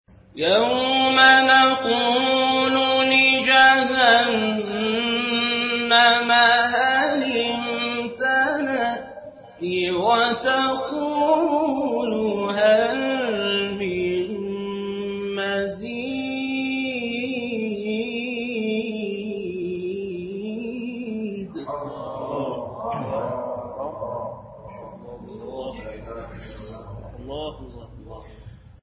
گروه شبکه اجتماعی: فرازهای صوتی از تلاوت قاریان بنام و ممتاز کشور را می‌شنوید.